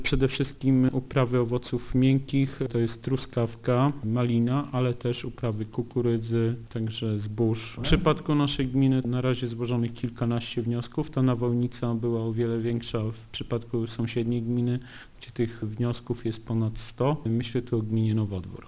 W naszej gminie największe szkody nawałnica wyrządziła w dwóch miejscowościach - Bramka i Gęsia Wólka - mówi wójt gminy Kłoczew Zenon Stefanowski: